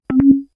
Añadido sonido a las notificaciones.
notify.wav